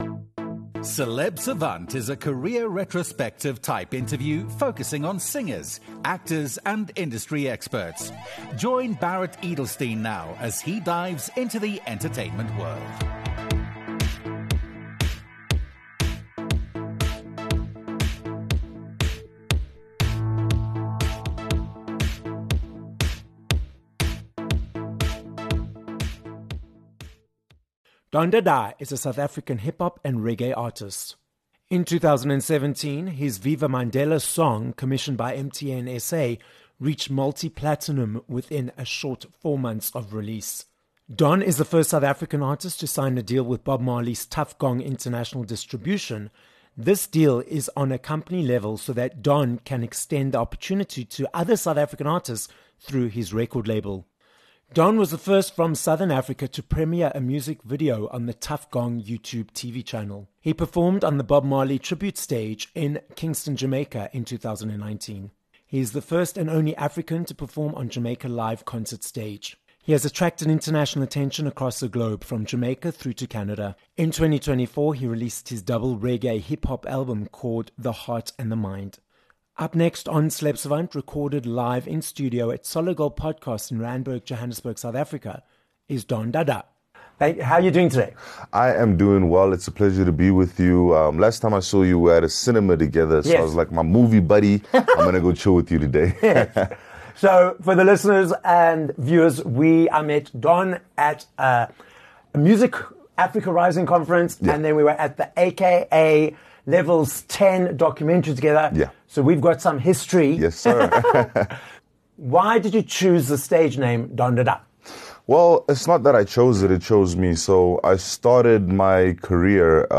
This episode was recorded live in studio at Solid Gold Podcasts in Johannesburg, South Africa.